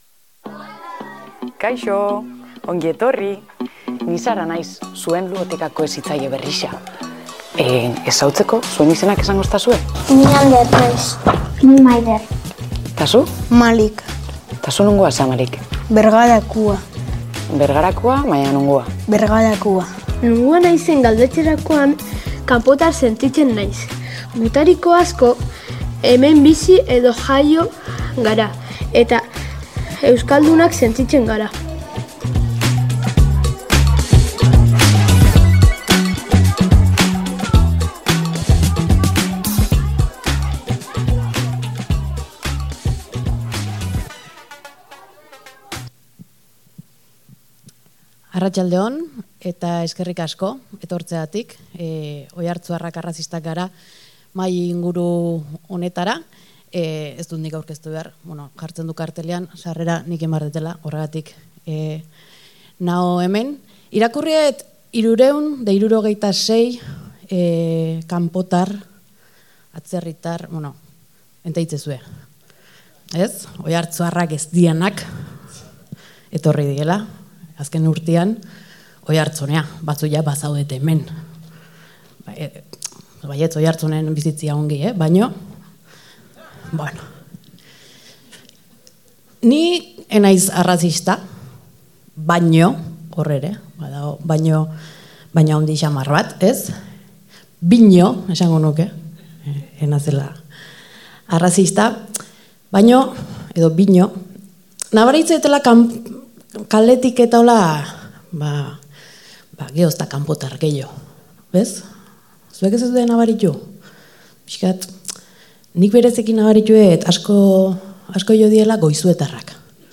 2025eko Harrera Astearen gai nagusia arrazakeriaren inguruko hausnarketa izan da, bizikidetza eta aniztasuna sendotzeko helburuarekin. Urriaren 17an Landetxen antolatu zuten mahai-ingurua izan dugu entzungai gaur Oiartzun Irratian.